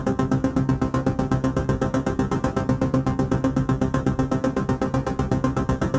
Index of /musicradar/dystopian-drone-samples/Tempo Loops/120bpm
DD_TempoDroneC_120-C.wav